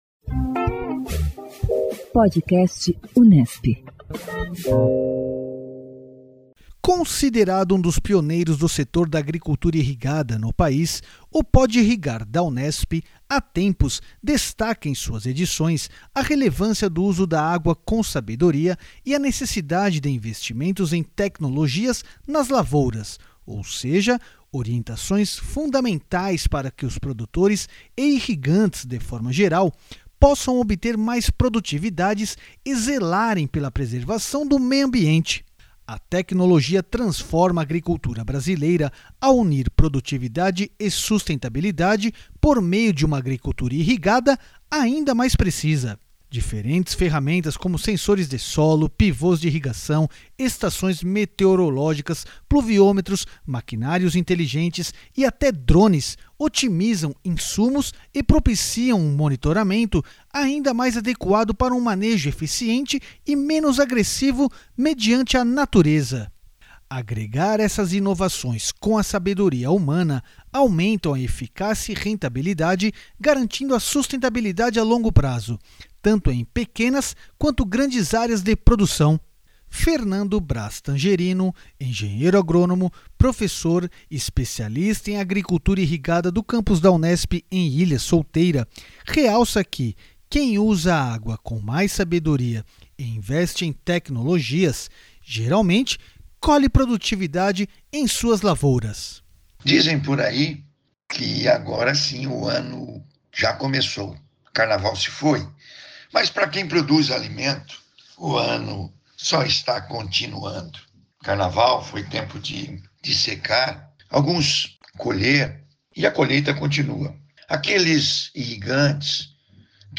O Podcast Unesp, em parceria com a Área de Hidráulica e Irrigação do câmpus de Ilha Solteira da Unesp, publica semanalmente noticiário sobre a agricultura irrigada e agroclimatologia. O objetivo é orientar as formas de manejo racional da água e energia.